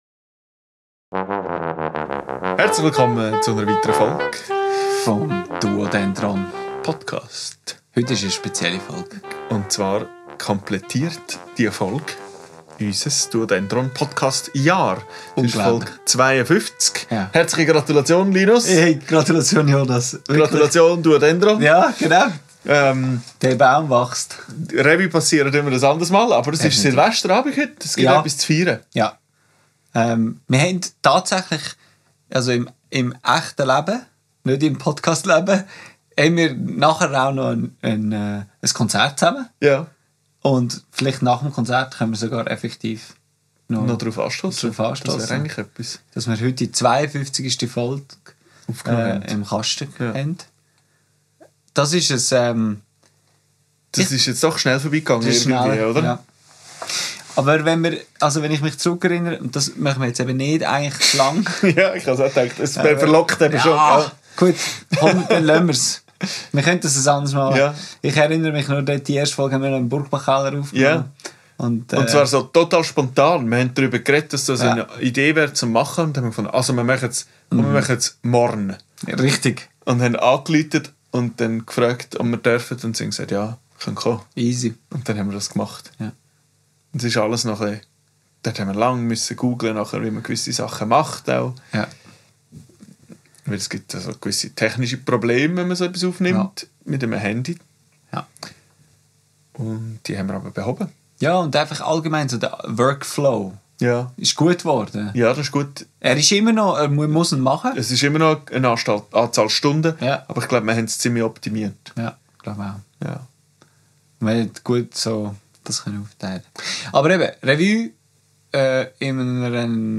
Live erarbeitet, vor der Kamera.